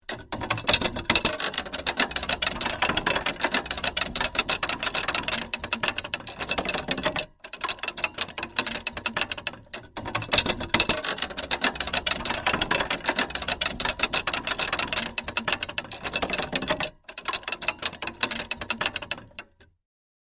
Download Free Gears Sound Effects | Gfx Sounds
Rusty-gears-mechanical-rattle.mp3